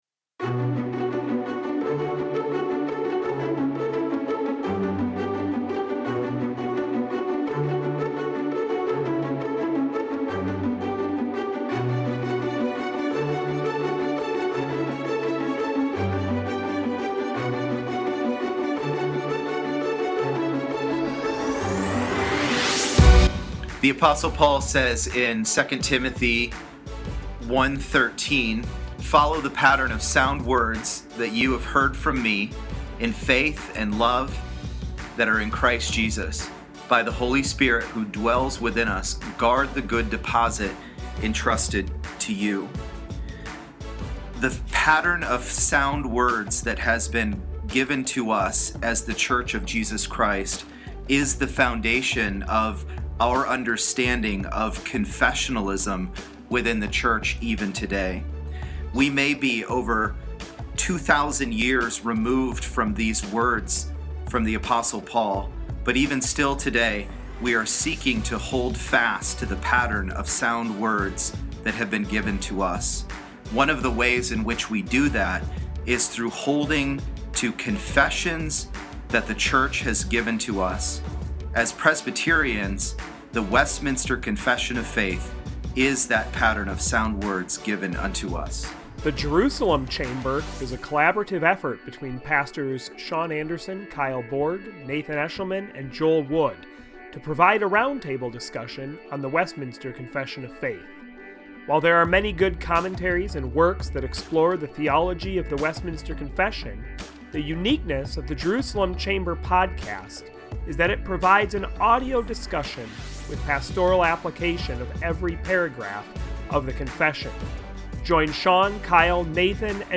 The uniqueness of The Jerusalem Chamber podcast is that it provides an audio discussion with pastoral application of every paragraph of the confession.